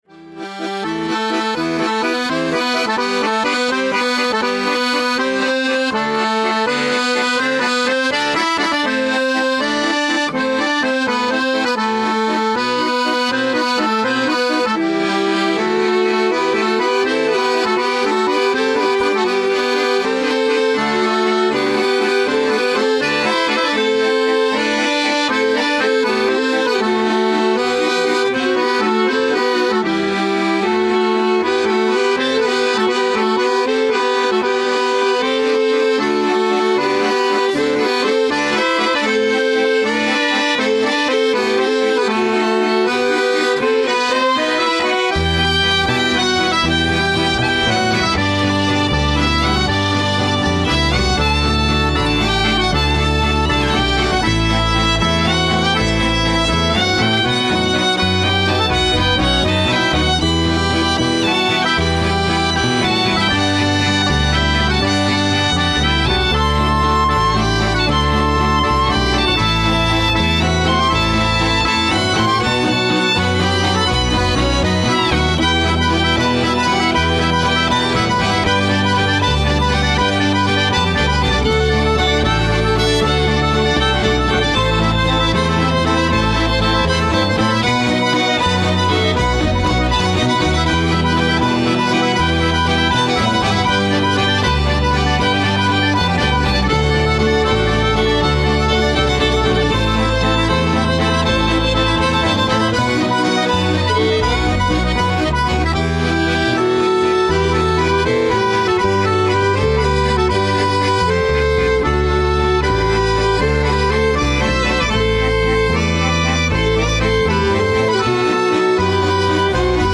ROMANTISCHE WALS
Wals Compilatie